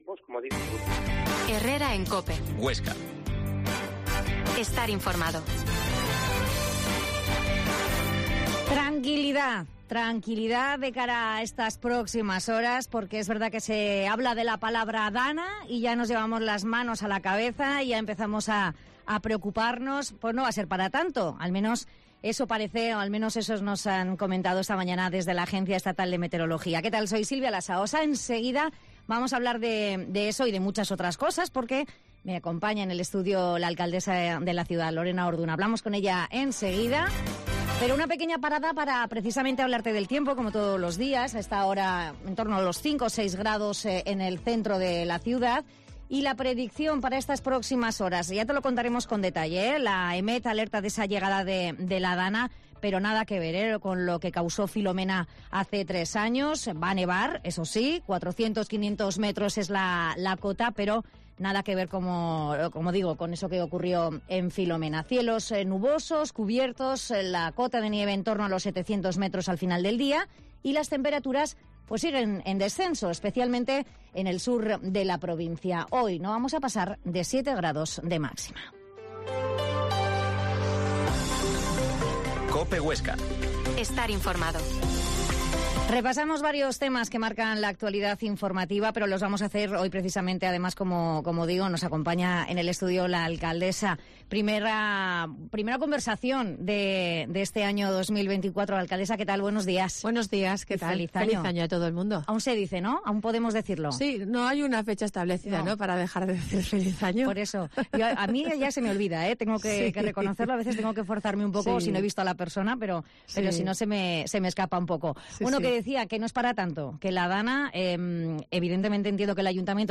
Herrera en COPE Huesca 12.50h Entrevista a la alcaldesa de Huesca Lorena Orduna